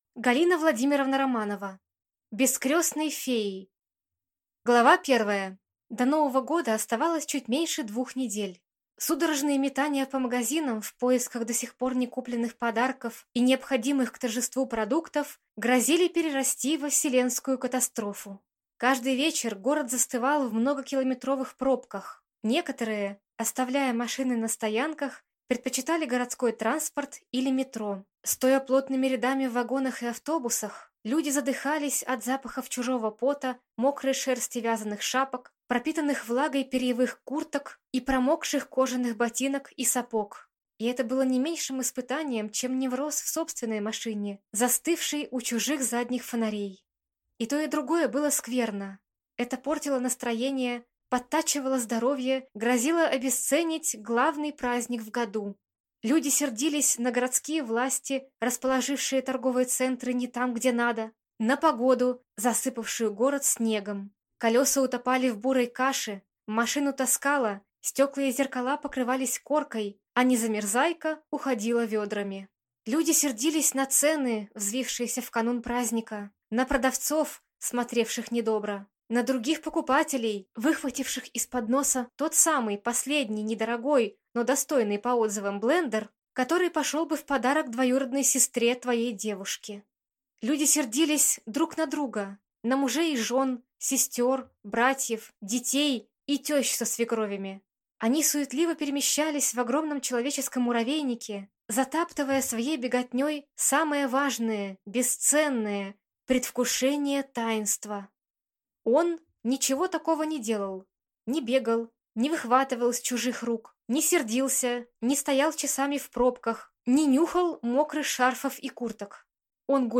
Аудиокнига Без крестной феи | Библиотека аудиокниг